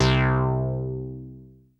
OSC MIXER 2.wav